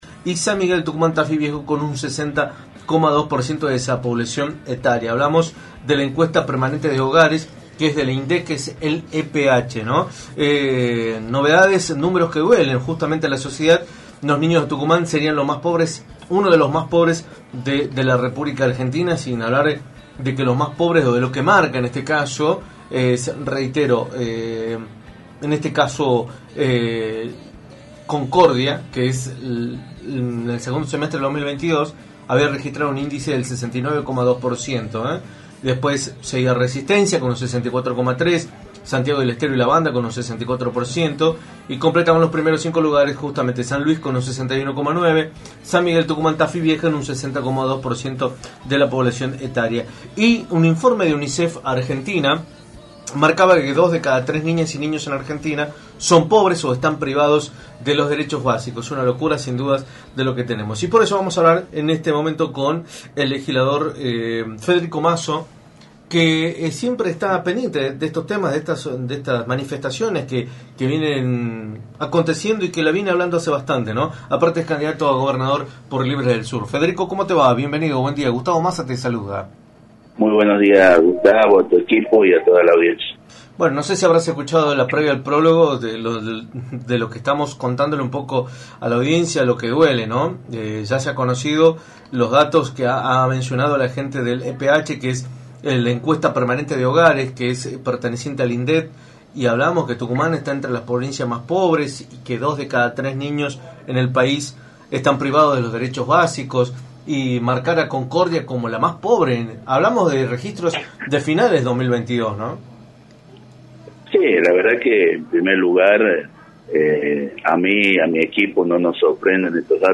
Federico Masso, Legislador y candidato a Gobernador por el espacio de Libres del Sur, analizó en Radio del Plata Tucumán, por la 93.9, las estadísticas reveladas por el INDEC, las cuales indican que Tucumán sería una de las provincias en donde existe la mayor cantidad de niños pobres en todo el país.